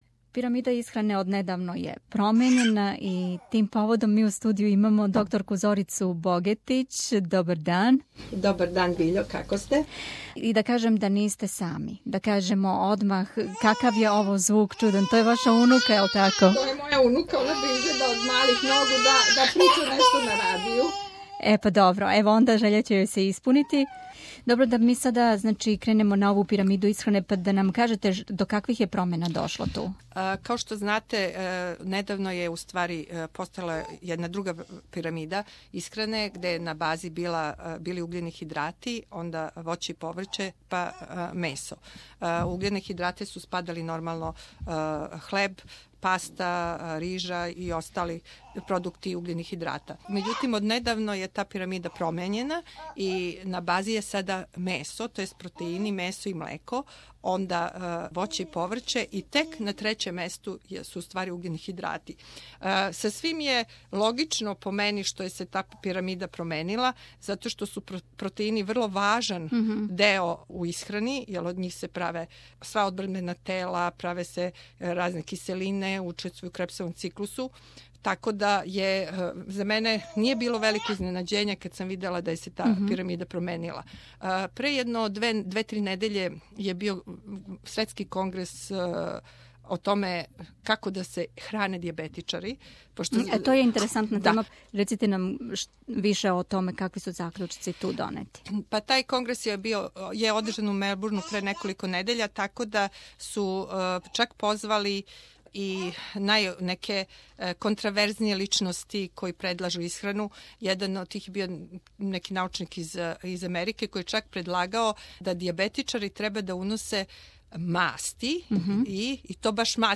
SBS studio